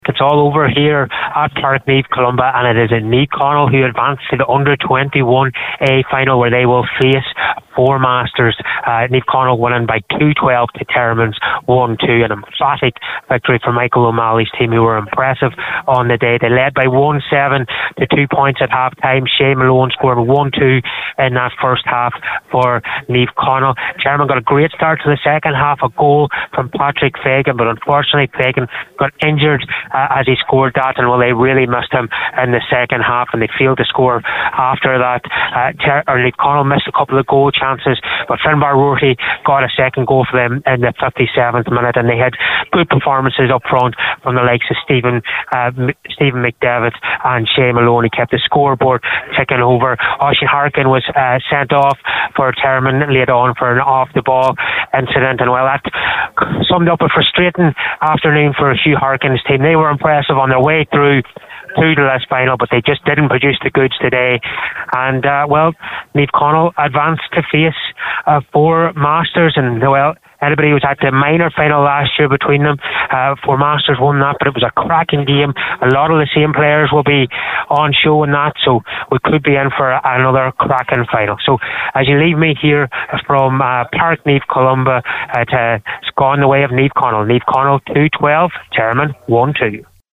was live at full time when Naomh Conaill booked their passage through to the decider…